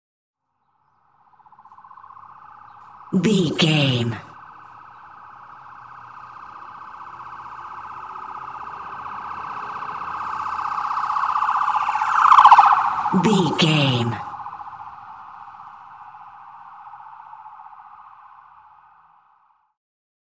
Ambulance Ext Passby Stress Siren 87
Sound Effects
urban
chaotic
anxious
emergency